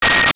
Sci Fi Beep 11
Sci Fi Beep 11.wav